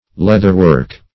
Meaning of leatherwork. leatherwork synonyms, pronunciation, spelling and more from Free Dictionary.